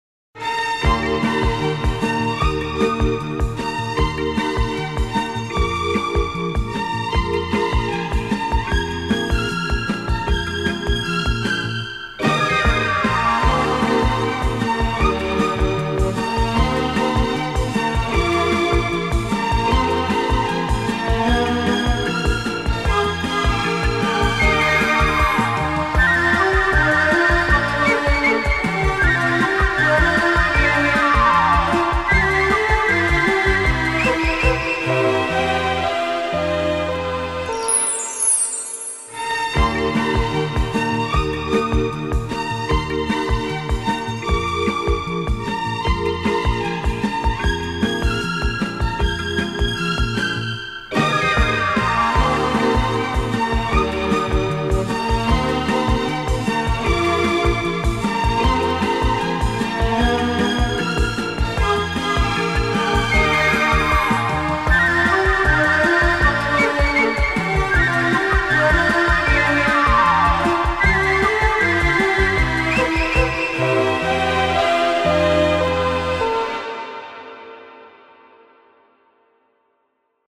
Genre:World Music